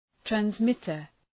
Shkrimi fonetik{trænz’mıtər, træns’mıtər}